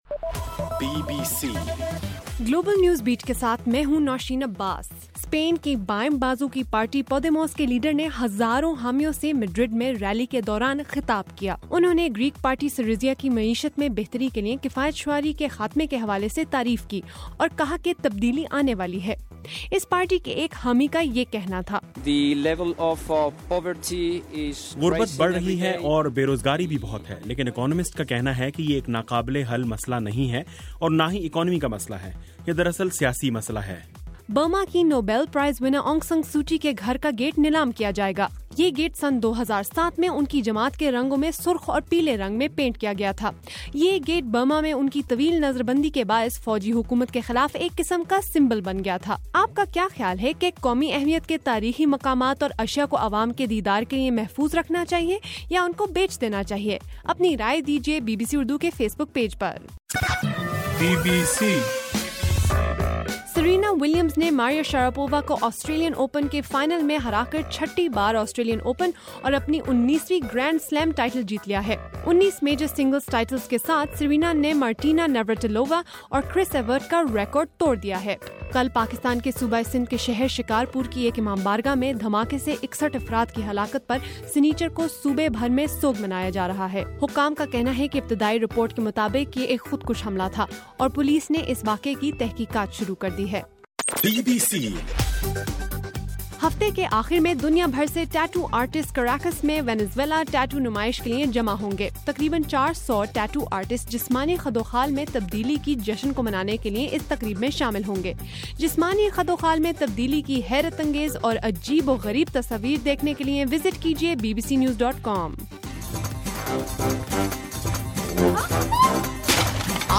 جنوری 31: رات 12 بجے کا گلوبل نیوز بیٹ بُلیٹن